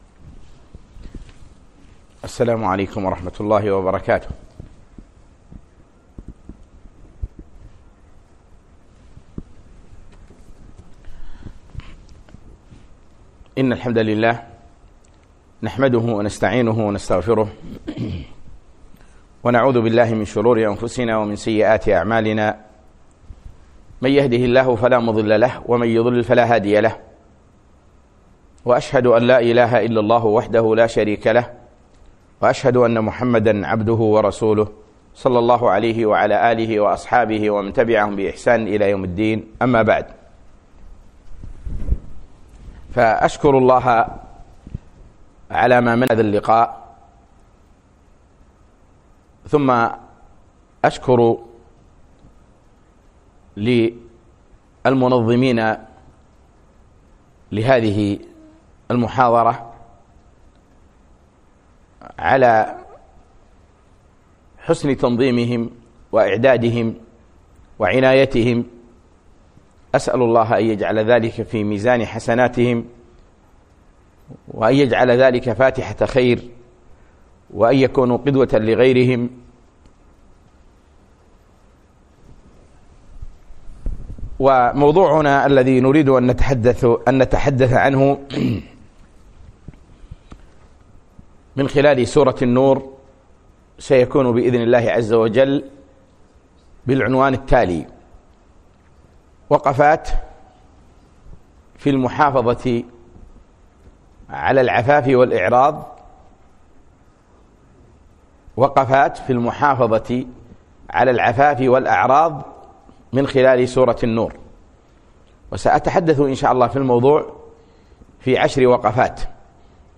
وقفات مع سورة النور - محاضرة - دروس الكويت
الأثنين 3 4 2017 الساعة 10 صباحا في مركة نعيمة الدبوس صباحي نساء